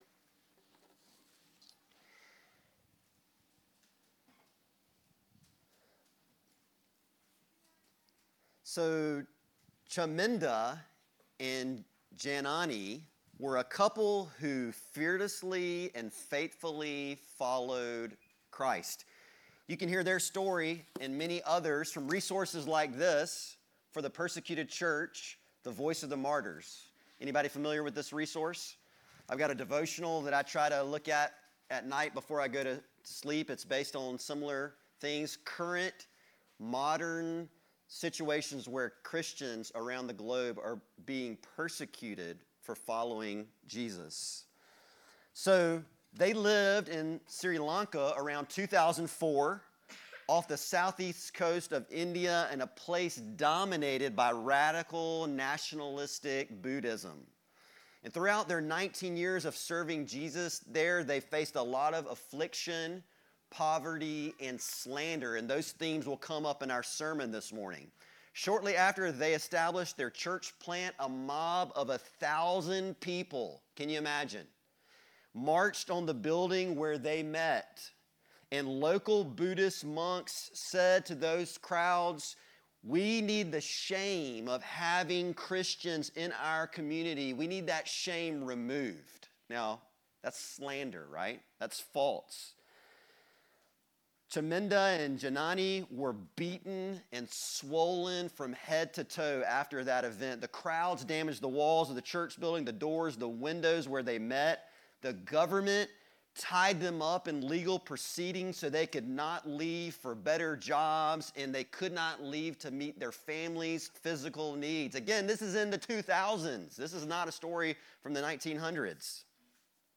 Sermons - Connection Fellowship